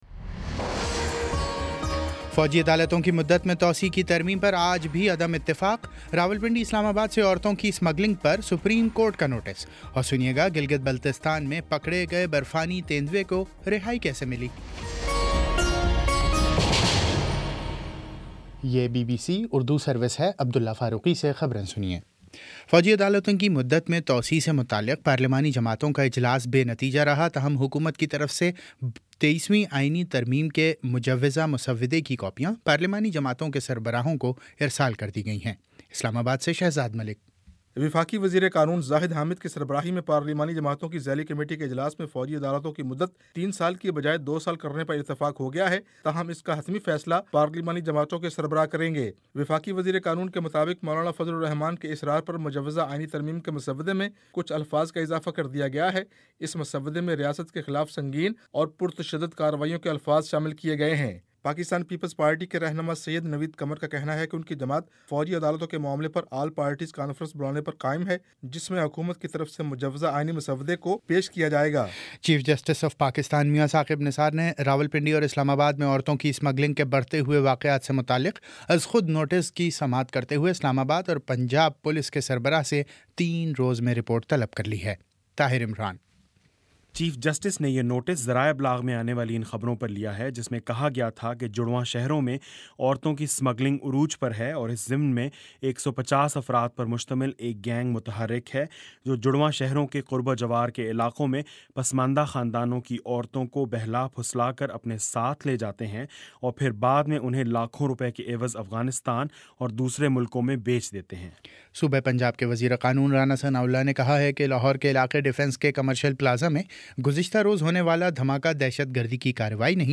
فروری 24 : شام سات بجے کا نیوز بُلیٹن